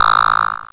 buzzhigh.mp3